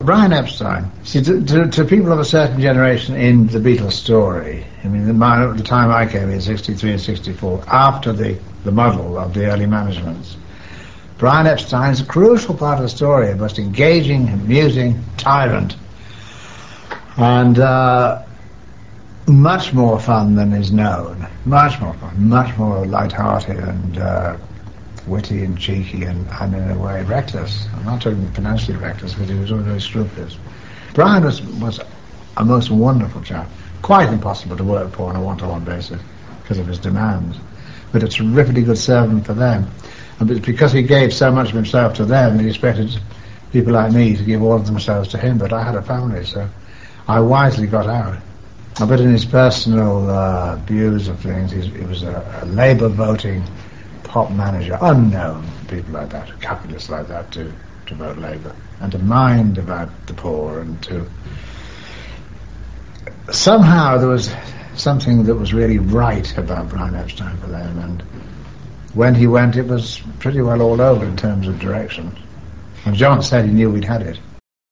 Here are a few never before seen extracts from those interviews: